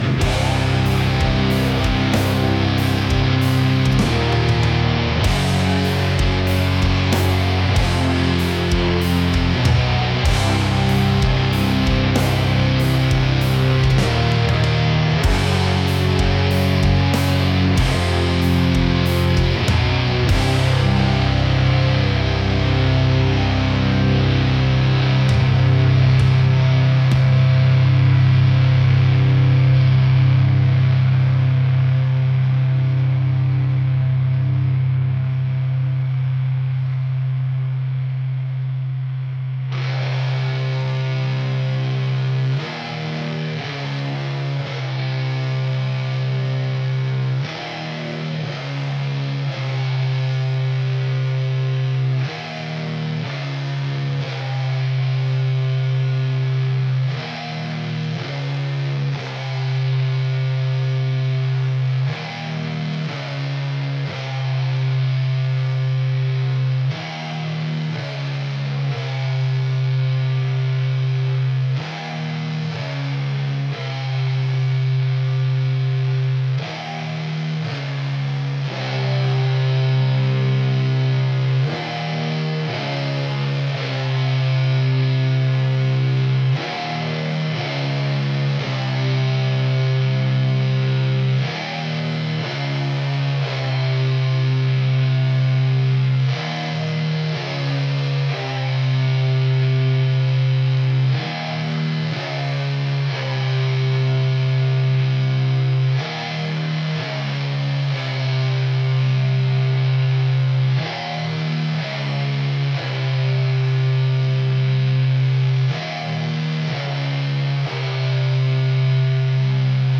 metal | heavy